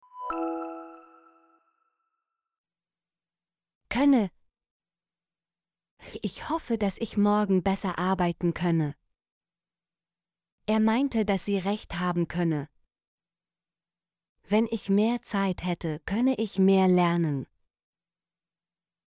könne - Example Sentences & Pronunciation, German Frequency List